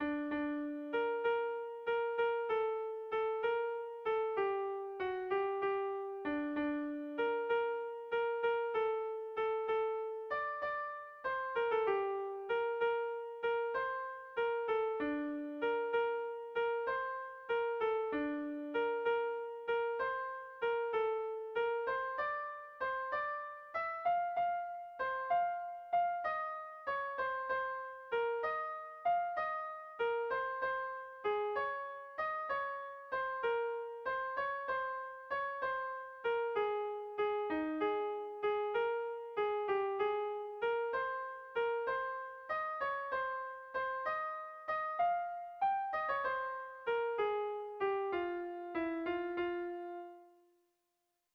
Kontakizunezkoa
Hamaseiko berdina, 8 puntuz eta 7 silabaz (hg) / Zortzi puntuko berdina, 14 silabaz (ip)
A1A2B1B2DE